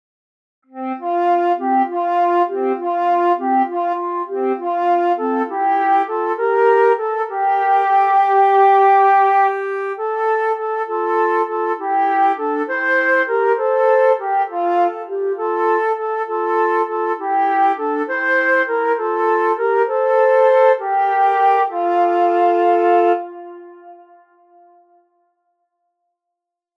Perwez Chant de quête Wa II